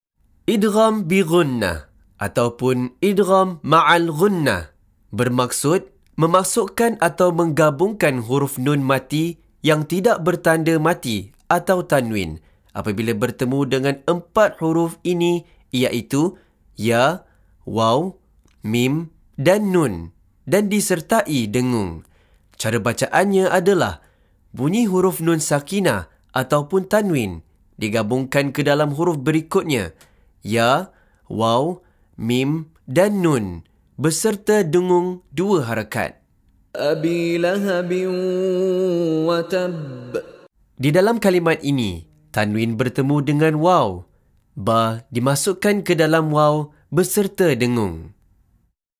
Contoh Bacaan dari Sheikh Mishary Rashid Al-Afasy
MEMASUKKAN/MENGGABUNGKAN bunyi huruf Nun Sakinah/TanwinTidak Selari dengan 4 huruf Idgham selepasnya beserta dengung 2 harakat.
Maka hendaklah digabungkan bacaan nun itu ke dalam huruf selepasnya kemudian didengungkan. Maka suara nun akan lenyap dan yang jelas didengari adalah suara huruf-huruf idgham itu.